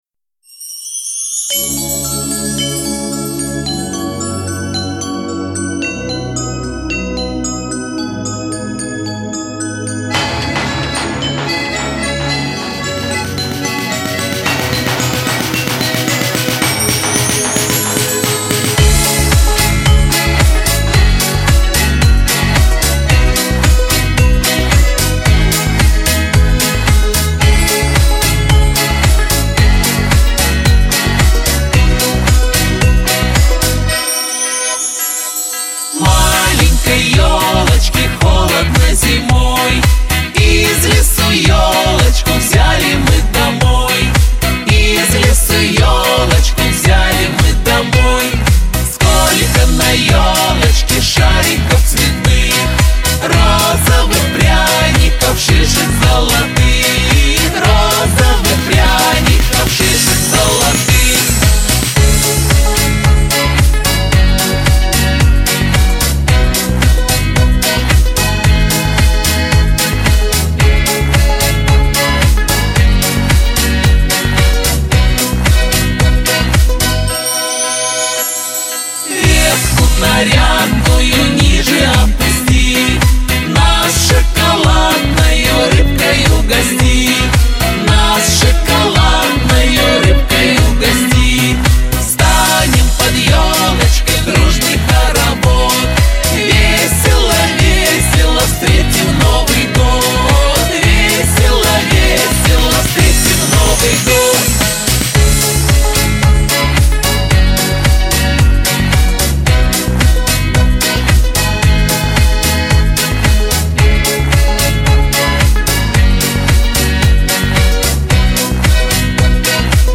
С "детской" скоростью.
(медленнее)